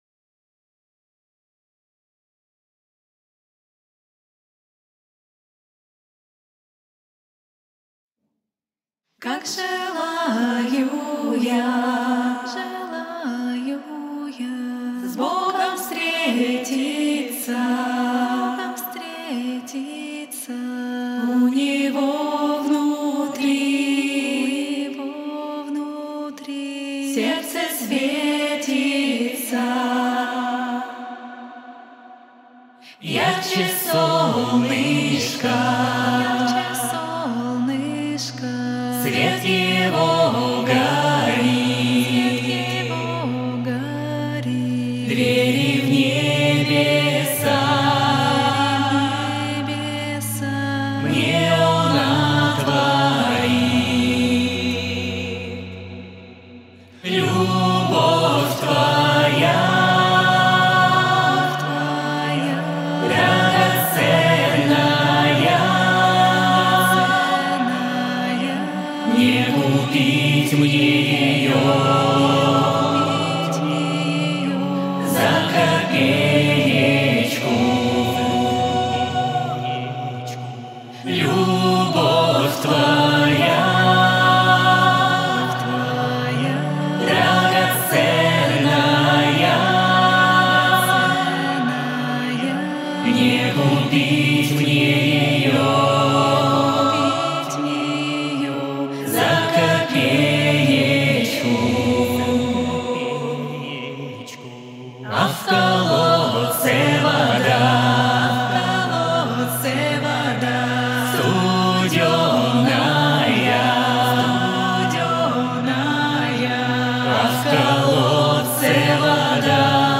акапелла, хор